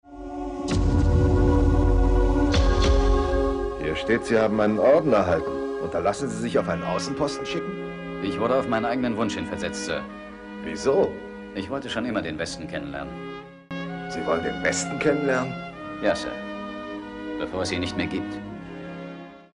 Frank Glaubrecht ~ Synchronsprecher
Ob in markant-seriösem oder männlich-sinnlichem Ton, Frank Glaubrecht leiht sie den größten Stars des Hollywoodfilms.
Frank_Glaubrecht_Kevin_Costner.mp3